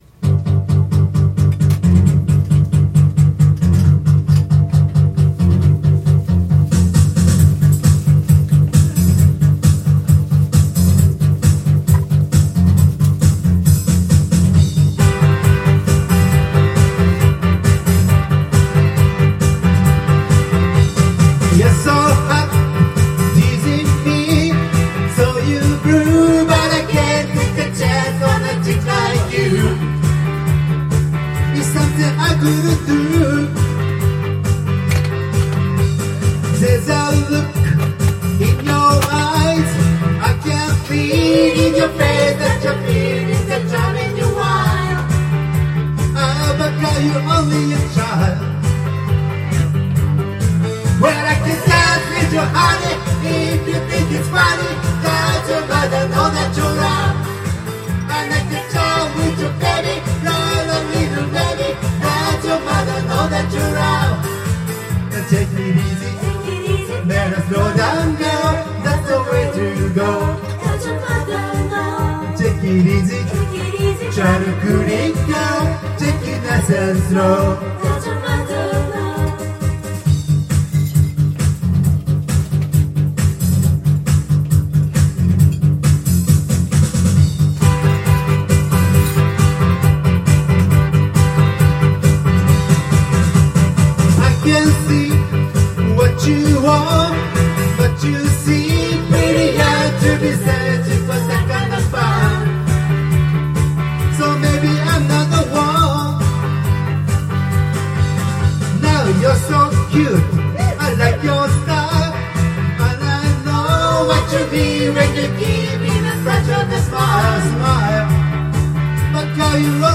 Duet & Chorus Night Vol. 18 TURN TABLE
11 【divaコーラスエントリー】